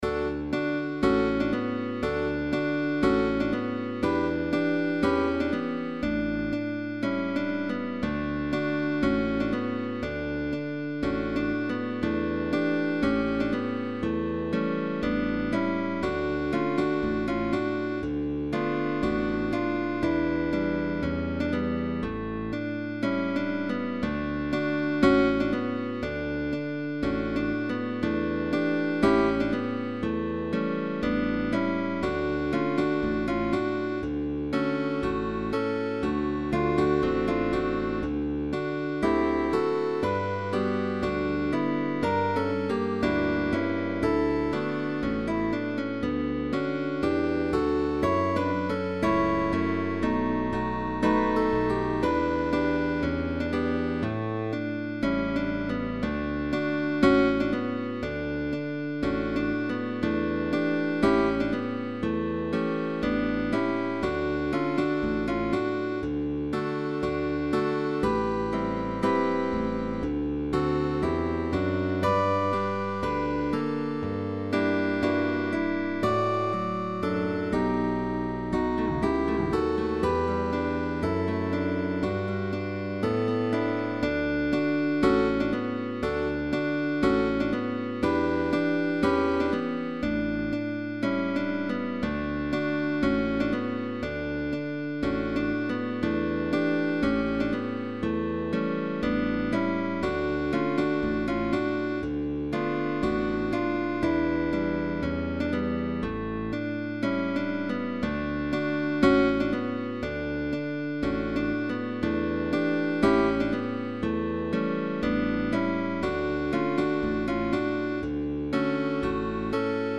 GUITAR TRIO
Pop music